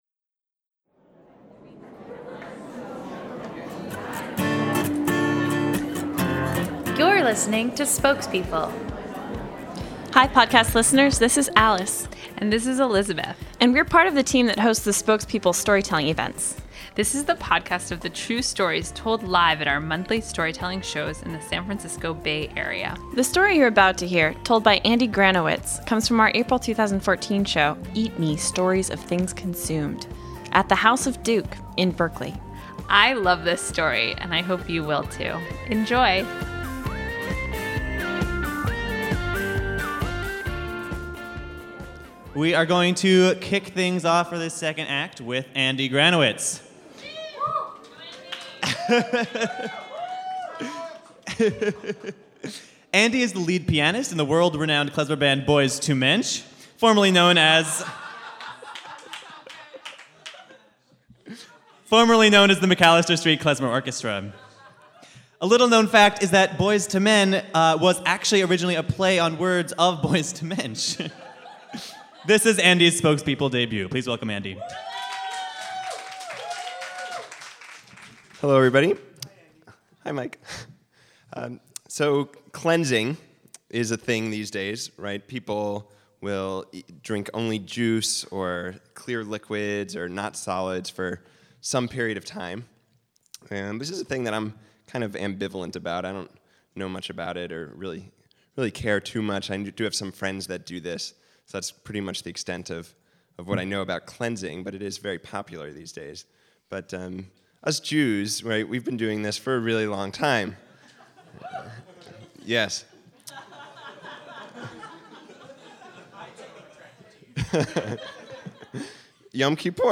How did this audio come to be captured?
Today's storyteller appreciates the elements of a good meal after his day of atonement in the land of culinary underachievement. This story comes from our April 2014 show, "Eat Me."